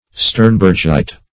Search Result for " sternbergite" : The Collaborative International Dictionary of English v.0.48: Sternbergite \Stern"berg*ite\, n. [So named after Count Kaspar Sternberg of Prague.]